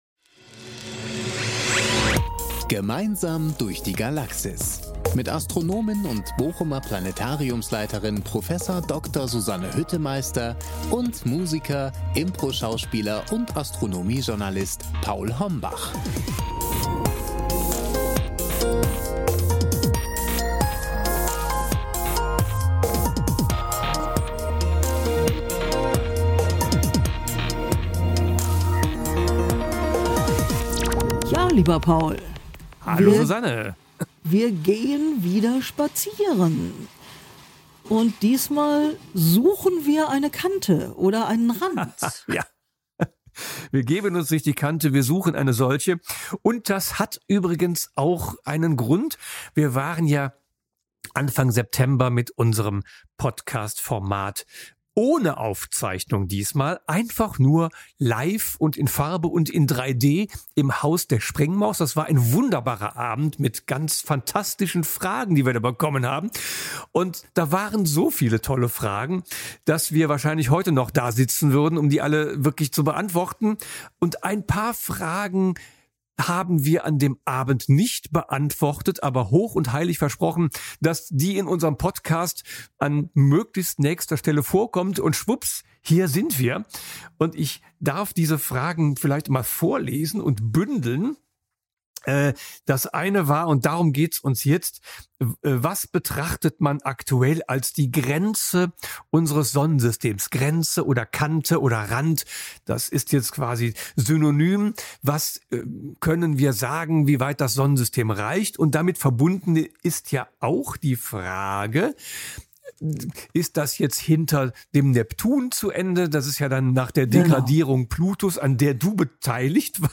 Astronomische Plaudereien aus dem Bochumer Planetarium